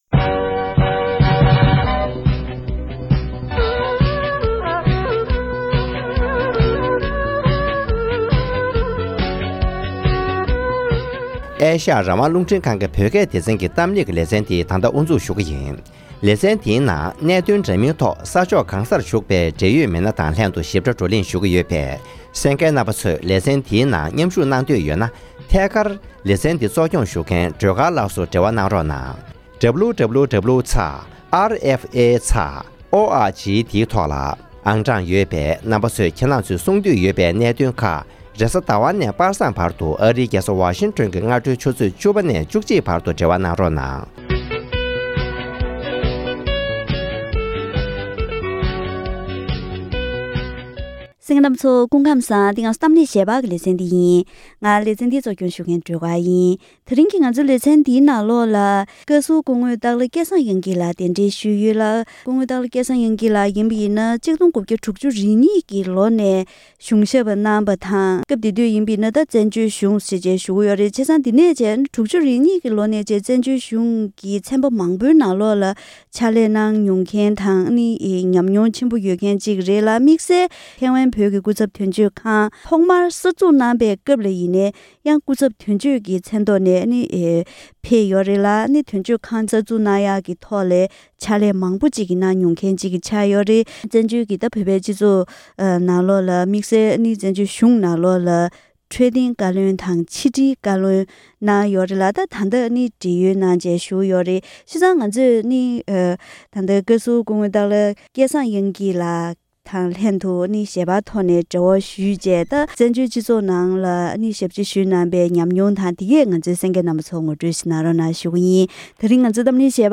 ༄༅། །ཐེངས་འདིའི་གཏམ་གླེང་ཞལ་པར་ལེ་ཚན་ནང་།